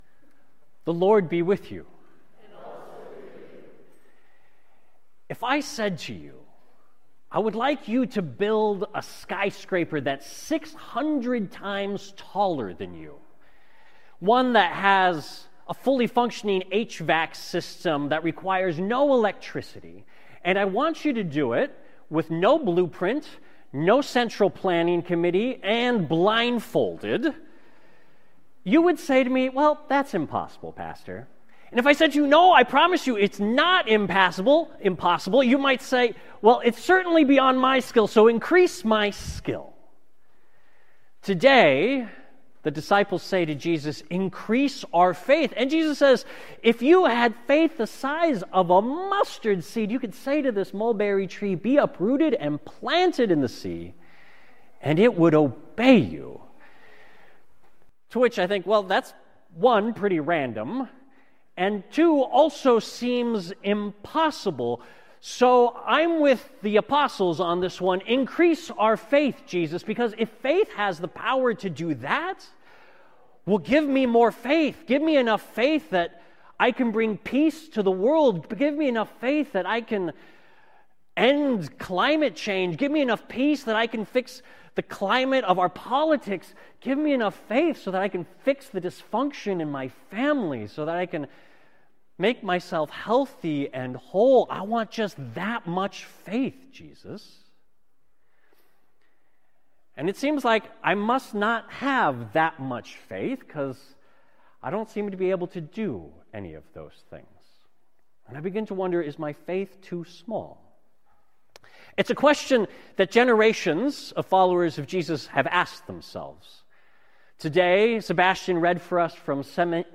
Sermons from Faith Lutheran Church | Faith Lutheran Church
(NOTE: Last Sunday we experienced technical issues that compromised our audio. Our staff and volunteers are working to fix this, and we have edited this audio to share it with as much clarity as we can. Thank you to our A/V team for being present during these hiccups and handling these situations with such care and grace.)